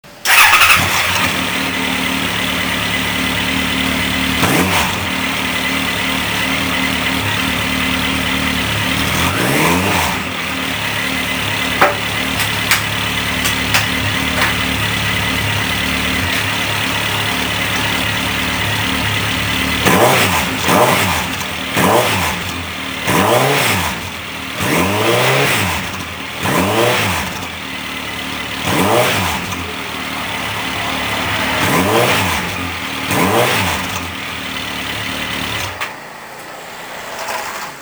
yoshimura TITAN CYCLONE with D.S.C Silencer
gsx1400with_yoshimura_cyclon.mp3